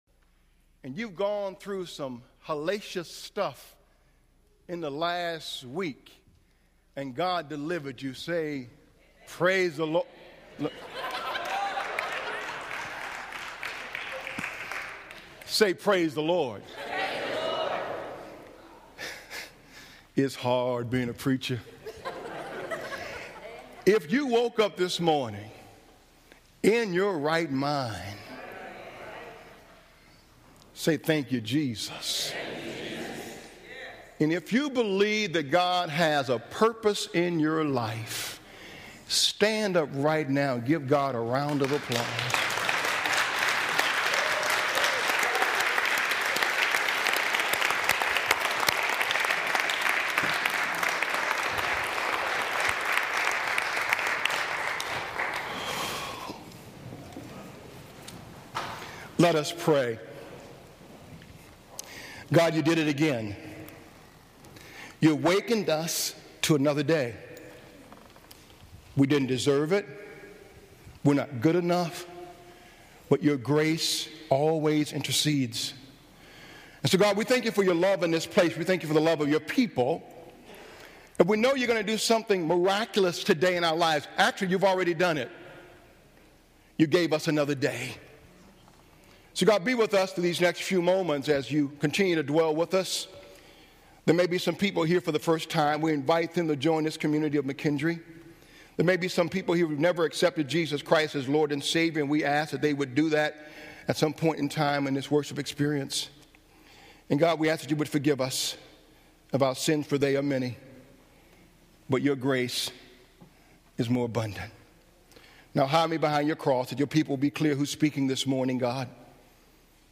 sermon 3_4_12.mp3